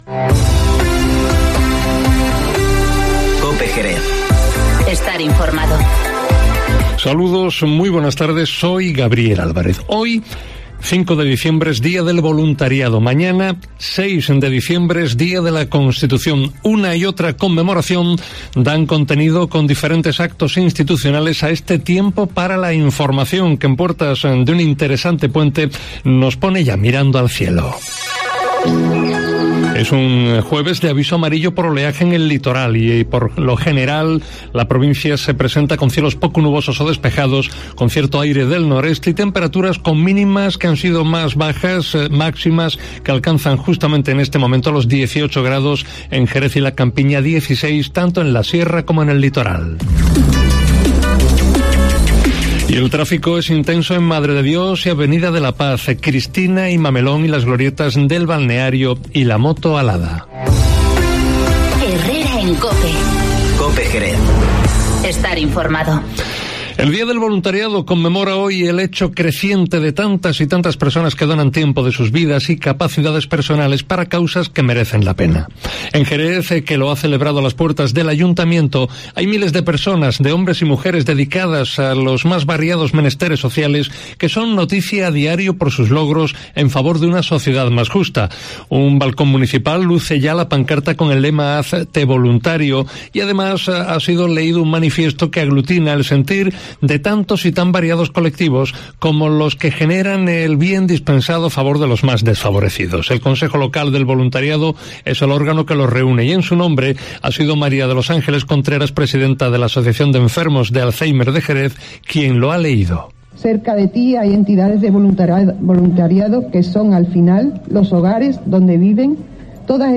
Informativo Mediodía COPE en Jerez 05-12-19